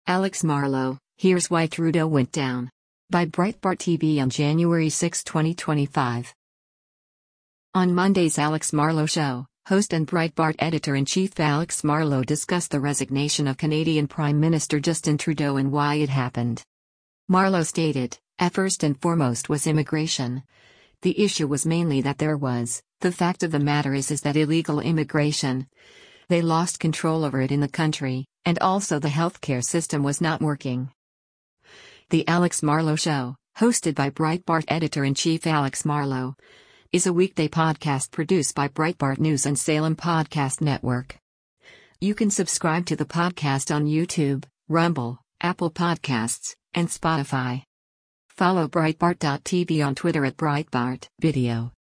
On Monday’s “Alex Marlow Show,” host and Breitbart Editor-in-Chief Alex Marlow discussed the resignation of Canadian Prime Minister Justin Trudeau and why it happened.